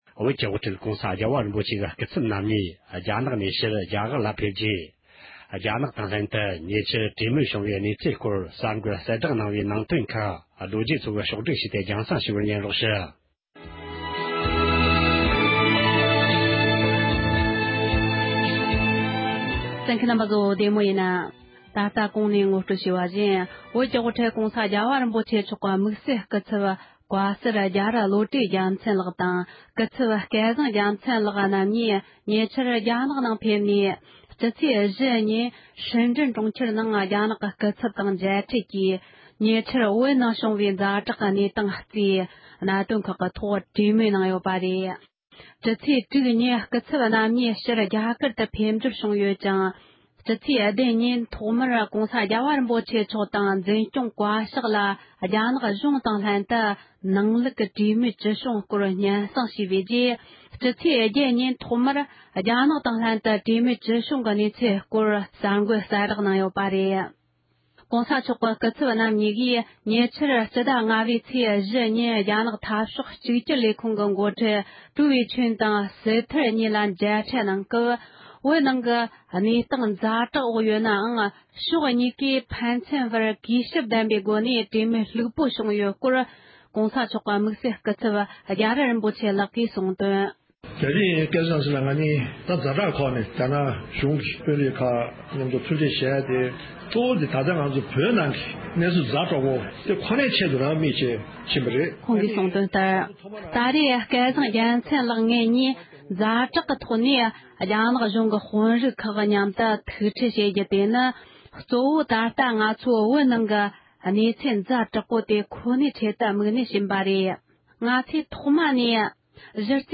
ཉེ་ཆར་བོད་རྒྱའི་གྲོས་མོལ་ཇི་ལྟར་བྱུང་ཡོད་མེད་སྐོར་༸གོང་ས་མཆོག་གི་དམིཊ་བསལ་སྐུ་ཚབ་རྒྱ་རི་རིན་པོ་ཆེ་ཡིས་གསུངས་ཡོད་པ།